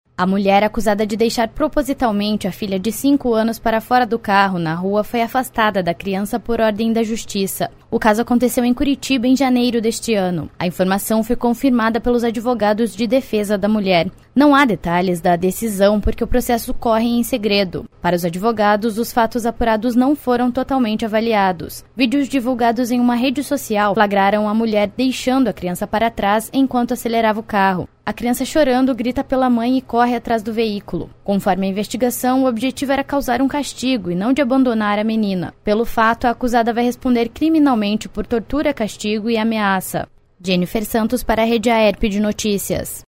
23.03 - BOLETIM SEM TRILHA - Mãe acusada de deixar propositalmente a filha para fora do carro em rua de Curitiba é afastada da criança pela Justiça